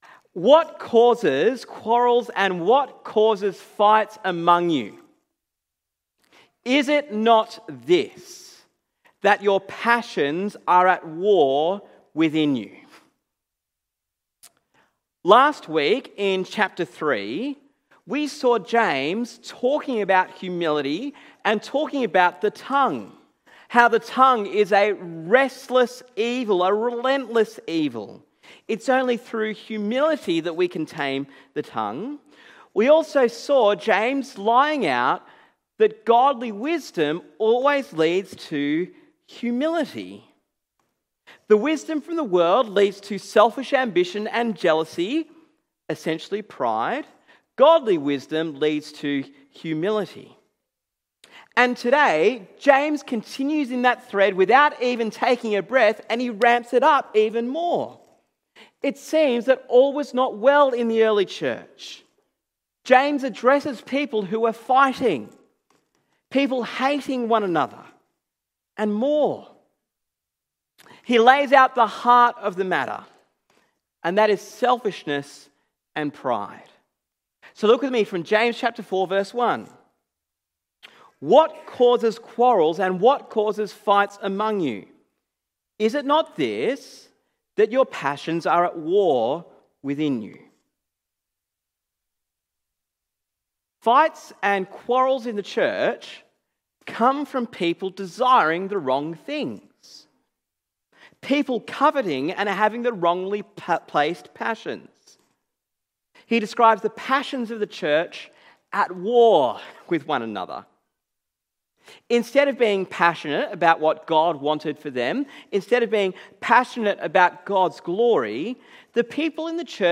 Sermon on James 4:1-10